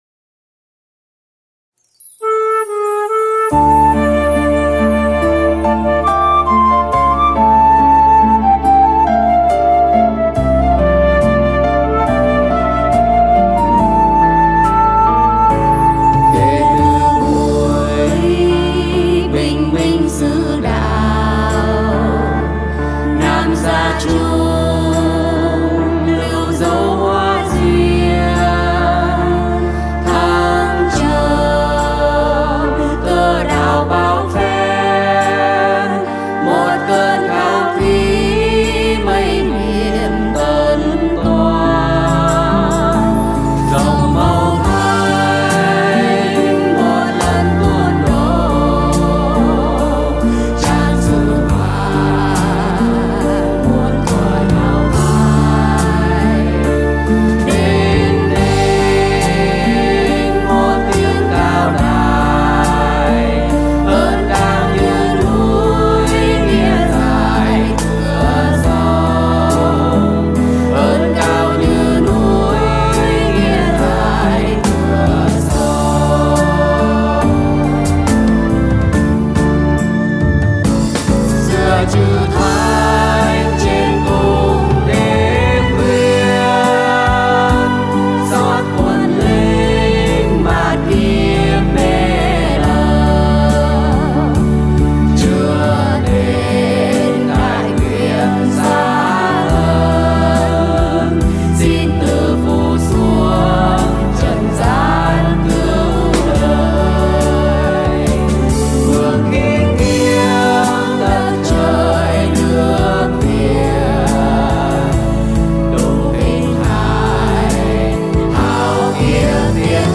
Thể Loại Đạo Ca
Tốp Ca: Dm / Nam: Am